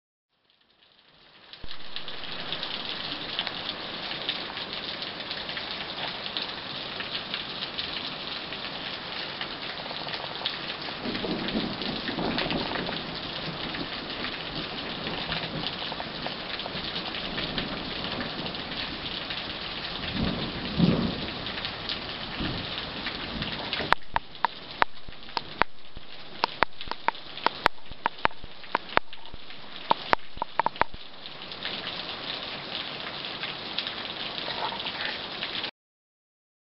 LLUVIA Y TRUENOS
Tonos EFECTO DE SONIDO DE AMBIENTE de LLUVIA Y TRUENOS
Lluvia_y_truenos.mp3